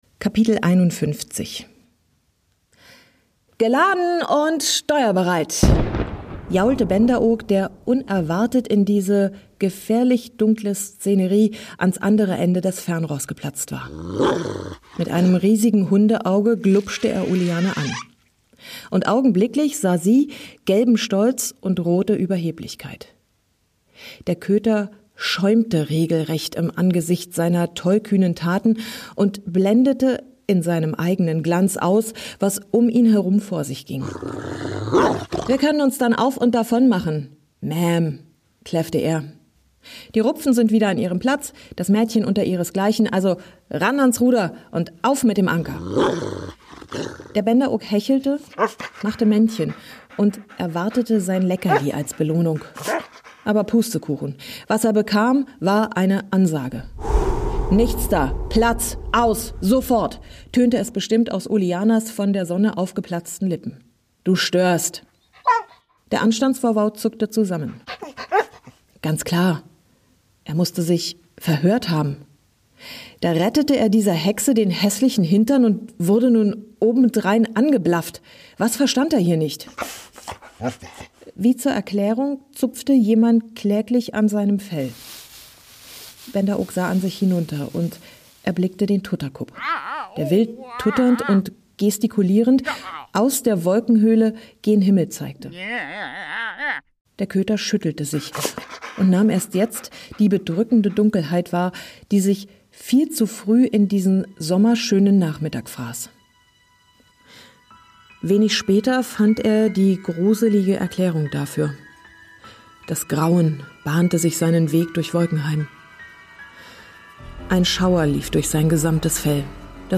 Ein atmosphärisches Hörerlebnis für alle, die sich gern davon und in die Wolken träumen. Eine Geschichte über all die Gefühle, die unser Leben erst bunt machen.